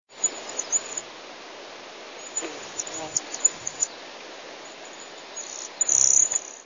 Название свое они получили от звуков, которые издают при пении: сви-ри-ри.
kedrovaya-sviristel-bombycilla-cedrorum.mp3